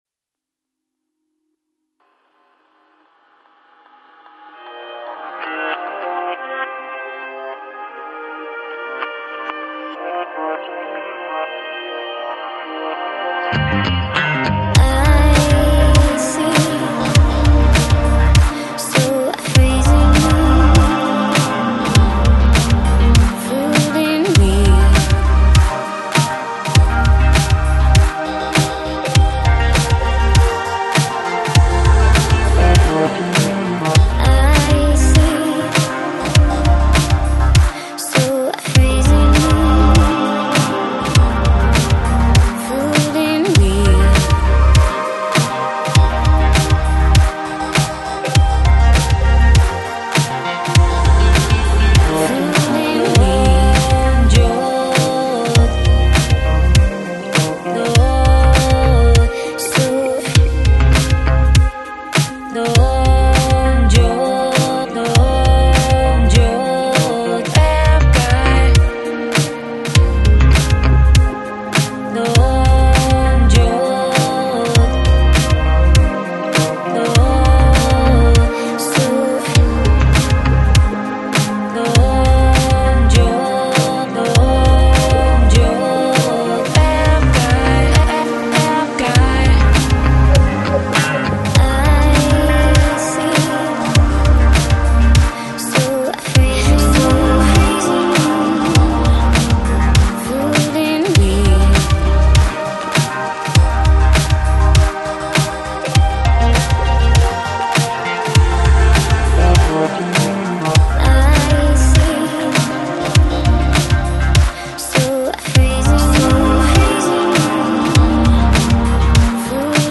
Жанр: Lounge, Chill Out